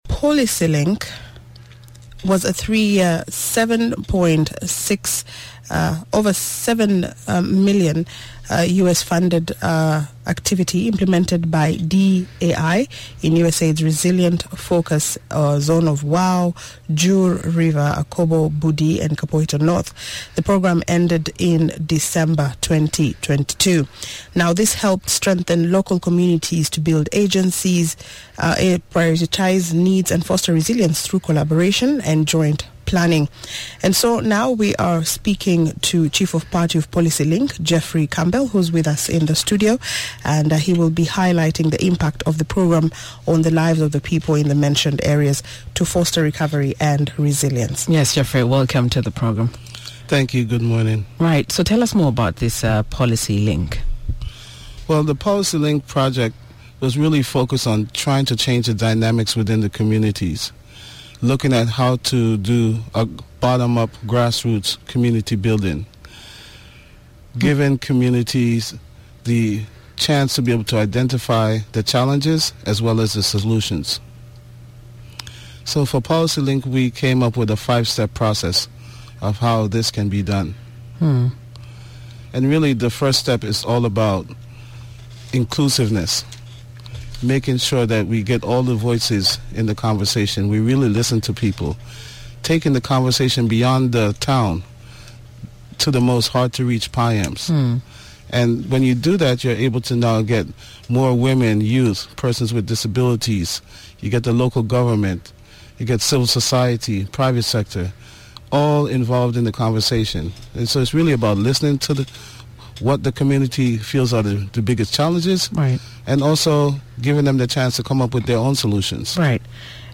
The program helped strengthen local communities to build agency, prioritize needs, and foster resilience through collaboration and joint planning. In the radio interview